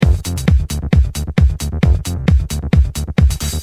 hooj_102_fast_loop.mp3